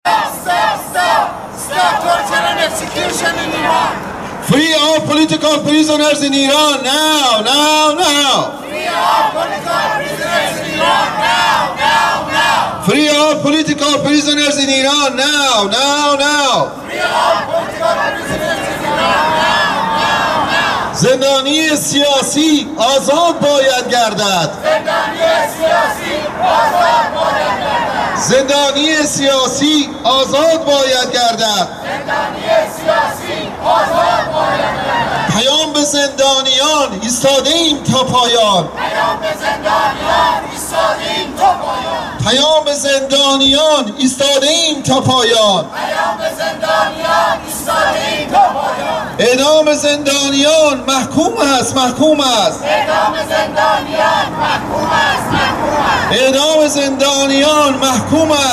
هواداران مقاومت و ايرانيان آزاده در سرمای بيش از ده درجه زير صفر، در ميدان “لست من” در مرکز شهر تورنتو تظاهراتی با عنوان نه به اعدام، نه به آخوند روحانی برگزار کردند.